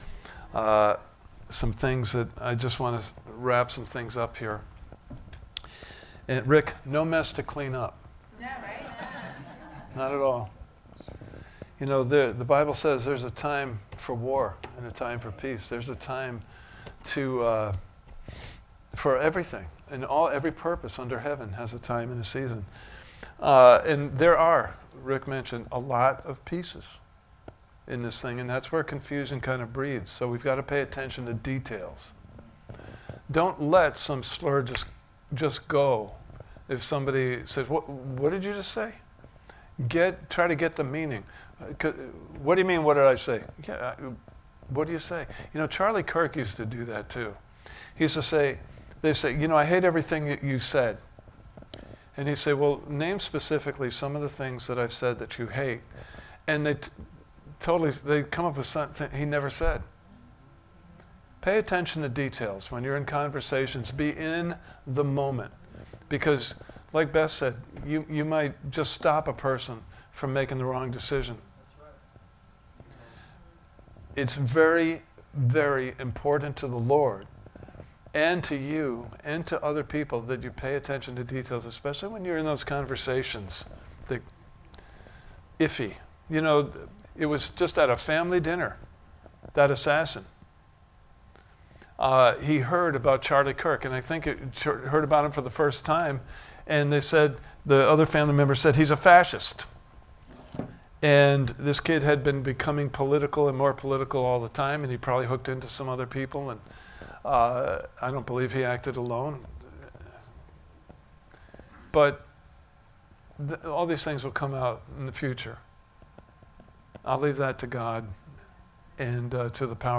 Prepared Service Type: Sunday Morning Service « Part 1